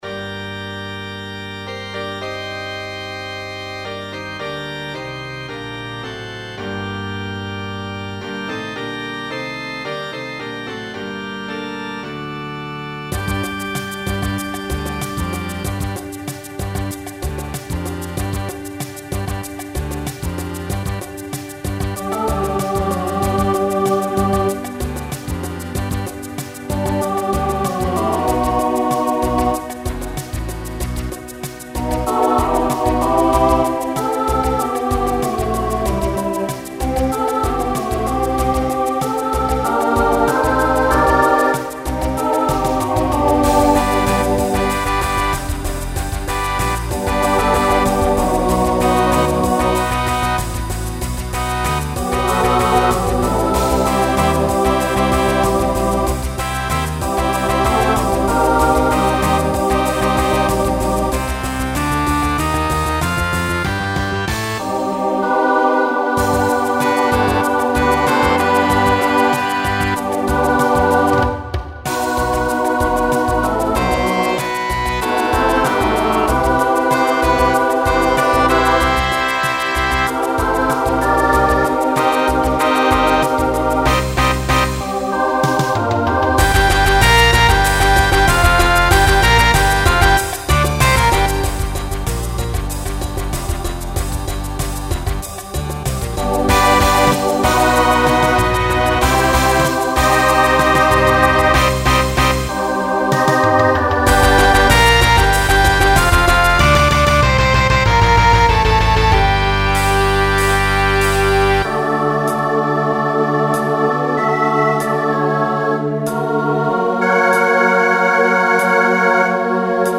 Genre Rock Instrumental combo
Mid-tempo , Story/Theme Voicing SATB